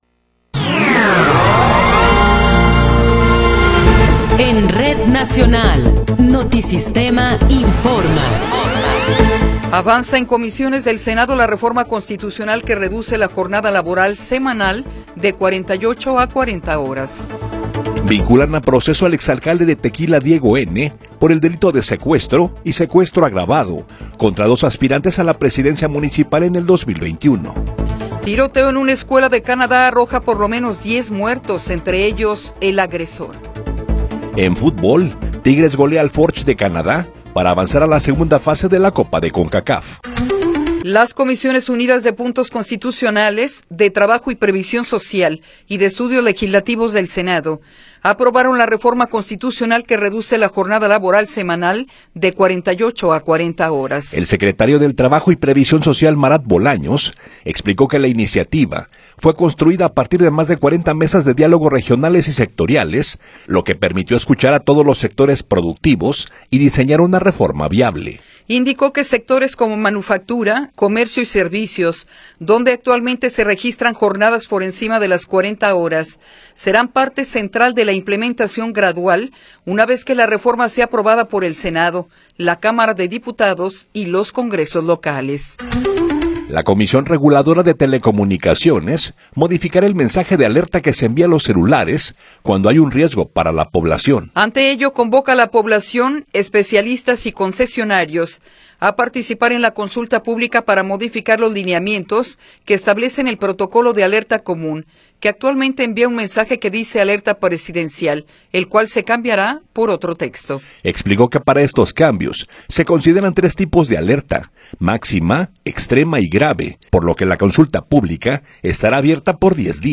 Noticiero 8 hrs. – 11 de Febrero de 2026